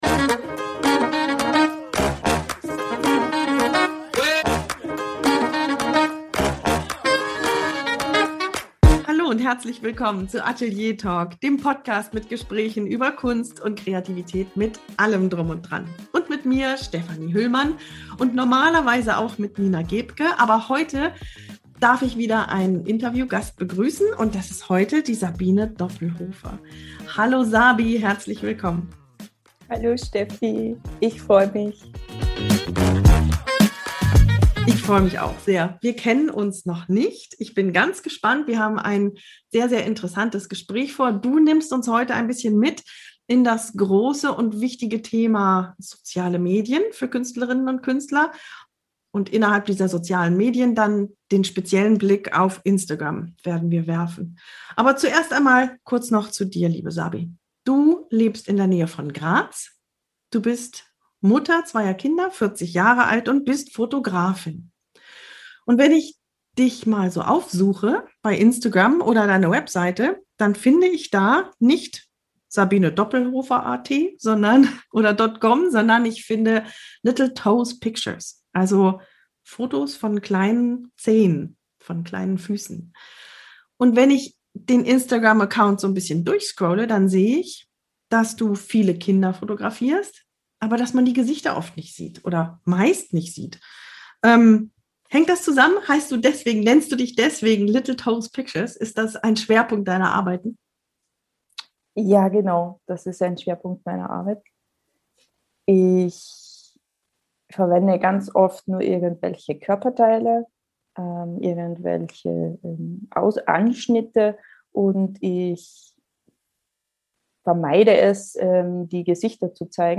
Zu Chancen und Risiken der Bilderplattform ein Gespräch